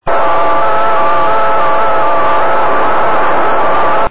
The recording shown below is from the 108.0 MHz frequency.
In (1) it is indicated that the VCO at 730 Hz transmitted the temperature at the nose cone, while the 560 Hz VCO showed temperature data at the skin of the satellite.
Satellites were captured as they flew through the main lobe of the antenna.